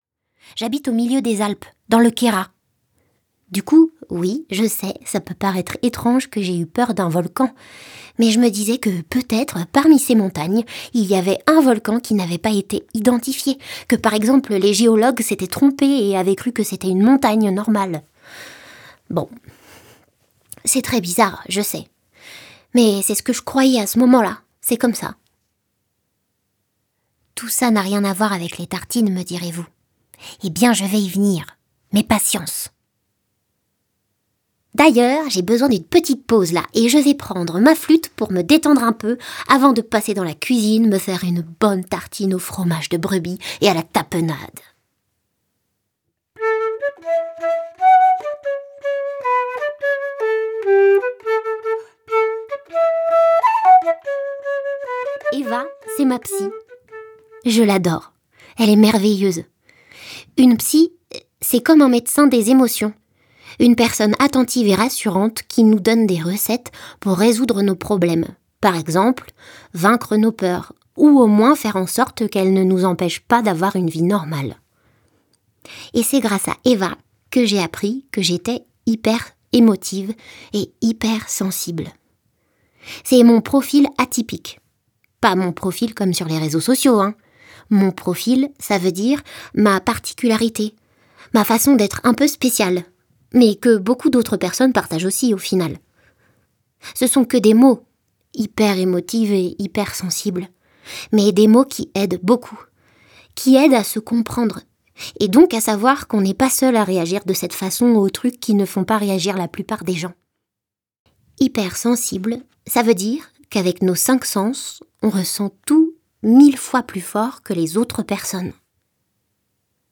2023-10-04 Dans un monologue aussi drôle que grave, Léonie se confie à cœur ouvert : du haut de ses neuf ans, elle met des mots sur les angoisses qui l’encombrent. Un exutoire vibrant, plein d’espoir et d’humour, qui fait jaillir en musique un tourbillon de sentiments et d’humeurs parfois incontrôlés.
Un exercice salutaire qu’elle ponctue de notes de flûte traversière mais aussi de nombreuses recettes : recettes pour appréhender ses craintes, recettes pour canaliser le stress, recettes de tartines aussi..! Ce livre audio est une porte ouverte sur le monde tel qu’il est perçu par une enfant hypersensible, mais il vient aussi rappeler à toutes les oreilles combien chacun·e a sa propre manière d’?tre spécial·e. Durée : 00H39 9 , 90 € Ce livre est accessible aux handicaps Voir les informations d'accessibilité